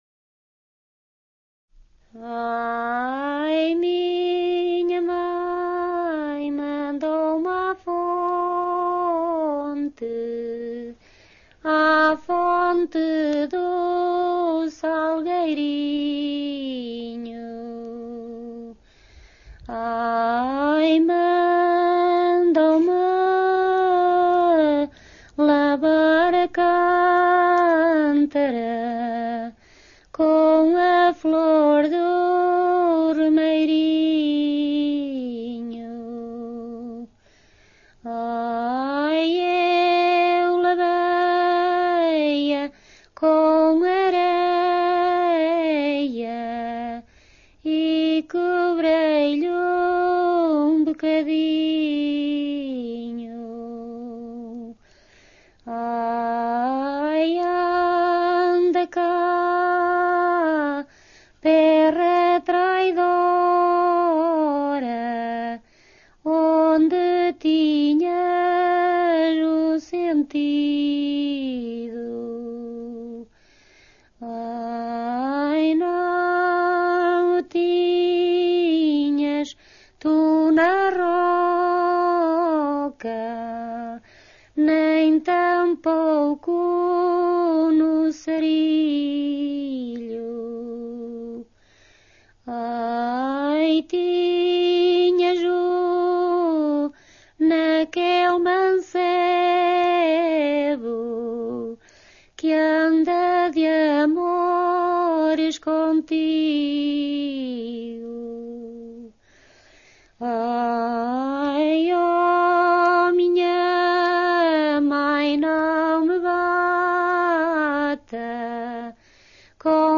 som_a_fonte_do_salgueirinho_tras_os_montes-1.mp3